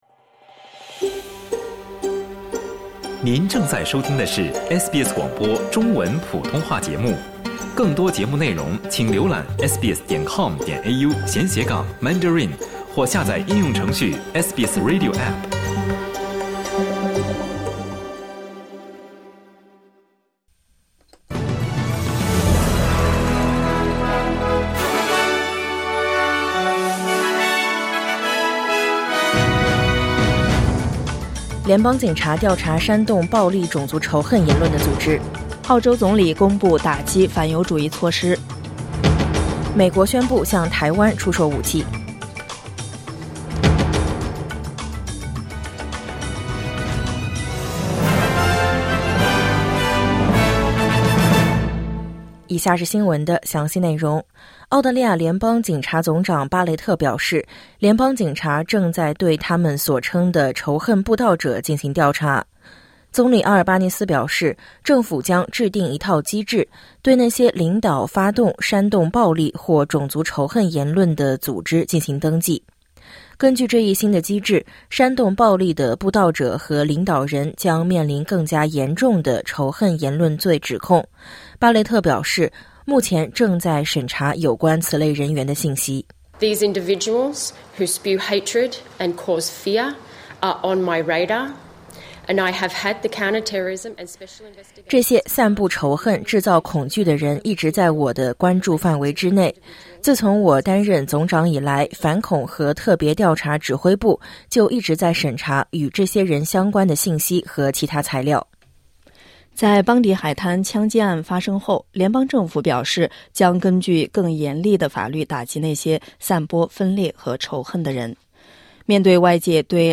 SBS早新闻（2025年12月19日）